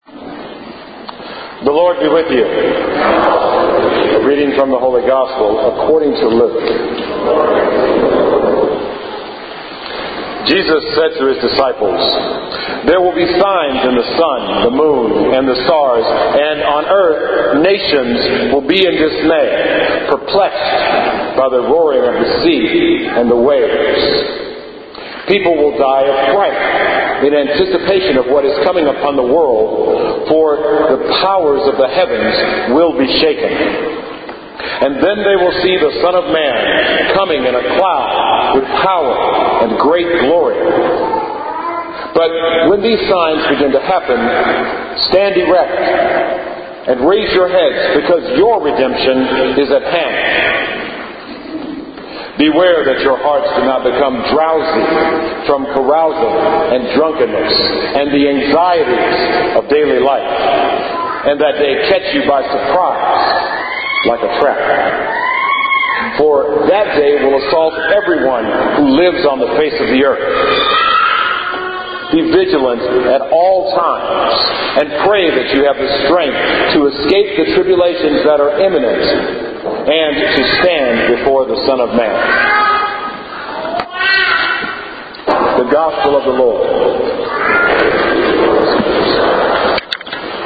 Audio for the Gospel reading is